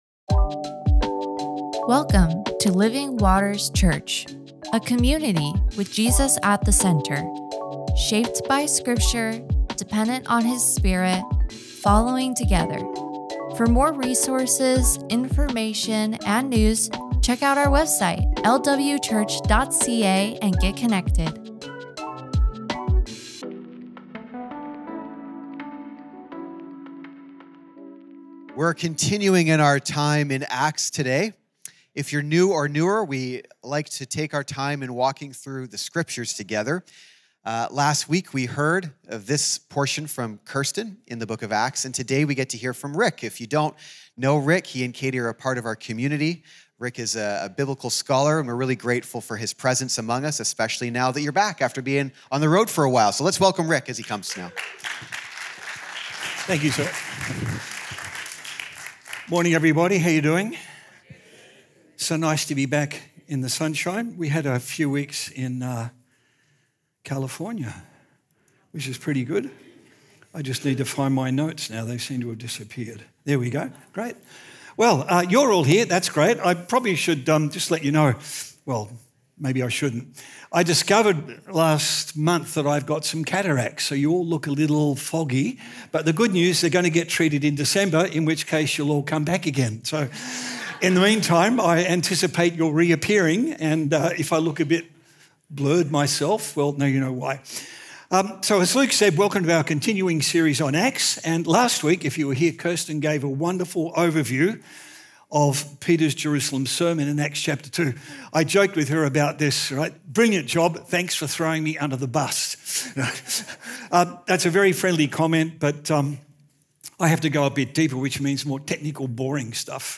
Sermons | Living Waters Church